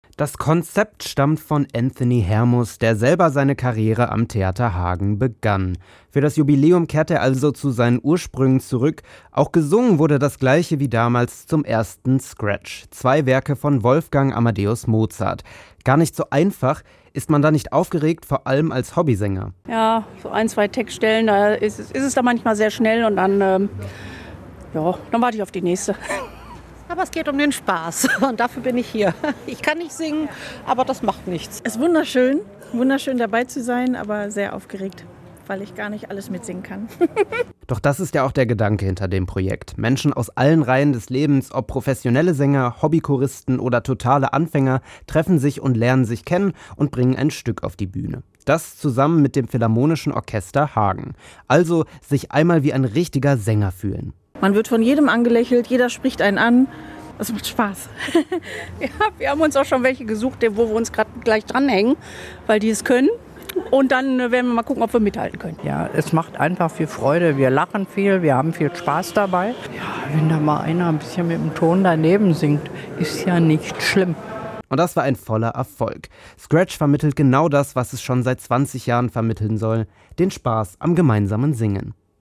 Der für den Tag erstellte SCRATCH-Chor hat dann am Ende des Tages in der Johanniskirche gemeinsam mit dem Philharmonischen Orchester Hagen ein Konzert gegeben.